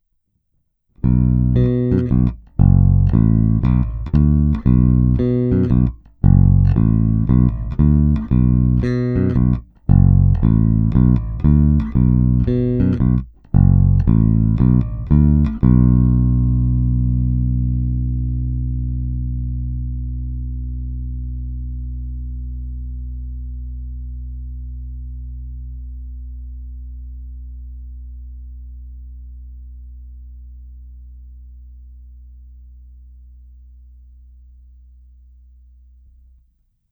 Není-li uvedeno jinak, následující nahrávky jsou provedeny rovnou do zvukové karty a s plně otevřenou tónovou clonou a s korekcemi na nule. Nahrávky jsou jen normalizovány, jinak ponechány bez úprav. Hráno nad použitým snímačem, v případě obou hráno mezi nimi. Na baskytaře jsou nataženy poniklované roundwound pětačtyřicítky Elixir Nanoweb v dobrém stavu.
Oba snímače